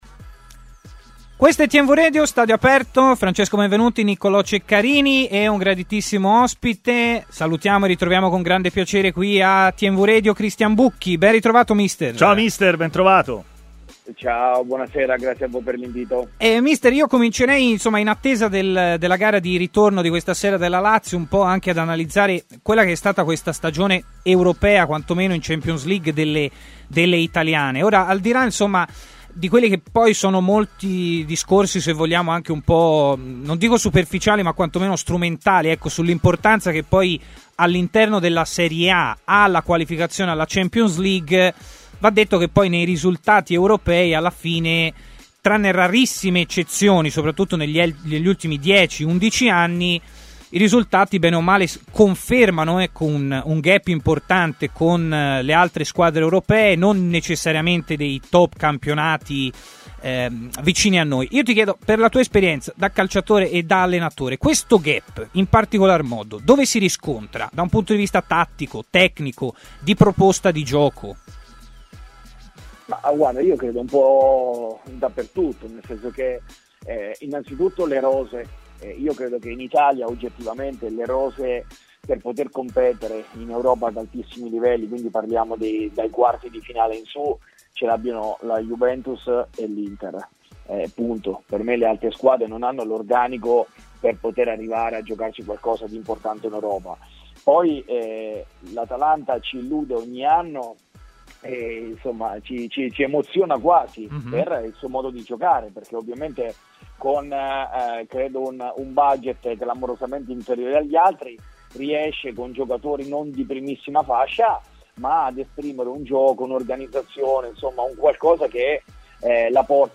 ha parlato a TMW Radio, nel corso della trasmissione Stadio Aperto